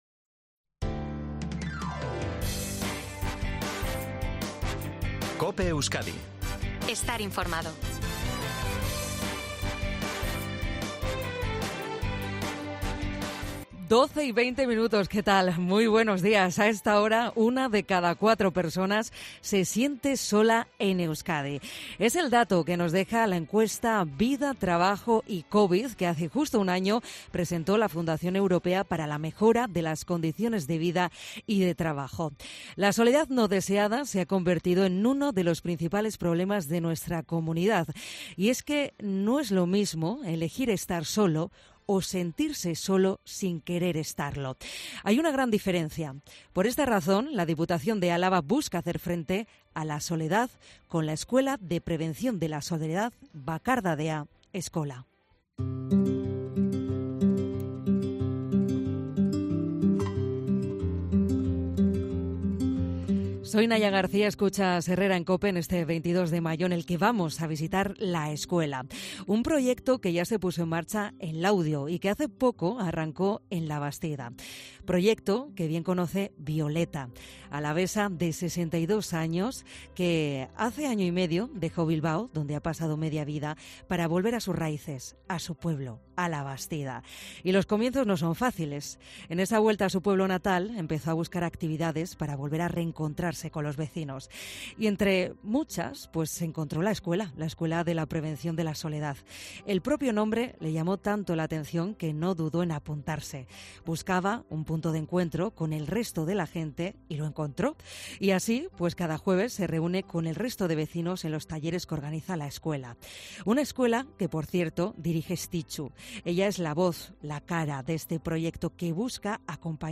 En HERRERA EN COPE Euskadi hemos visitado la escuela de Labastida para conocer de cerca su funcionamiento.